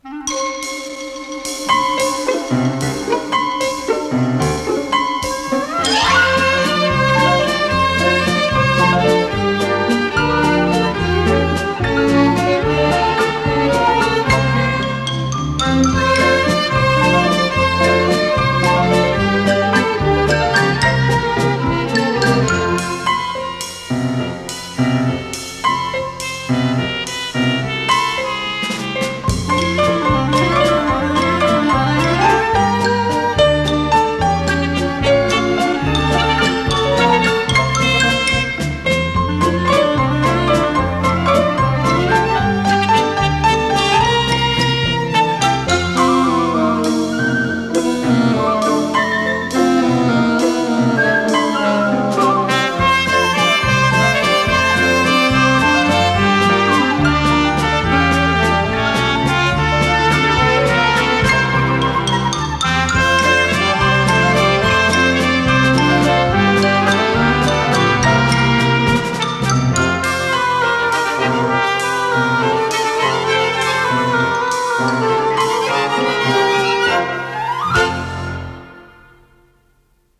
с профессиональной магнитной ленты
ПодзаголовокЗаставка, фа мажор
ВариантДубль моно